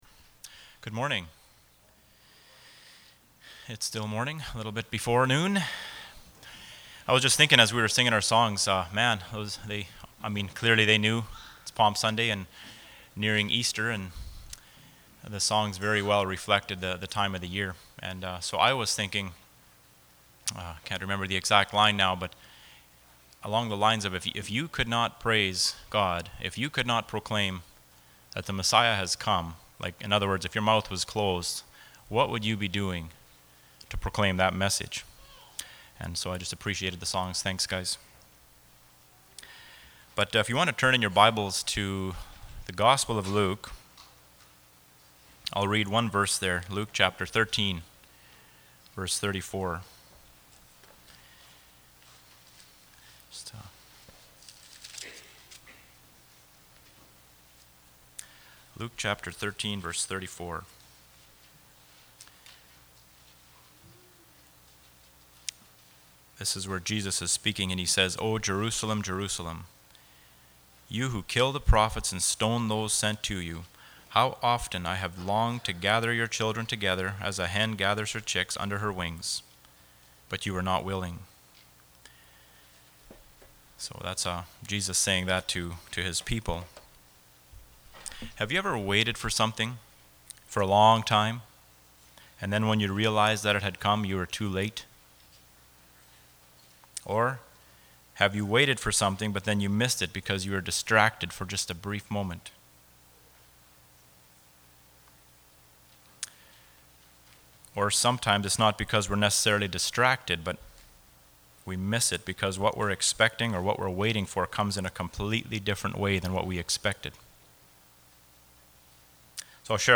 Luke 19:28-38 Service Type: Sunday Morning « Obey and Receive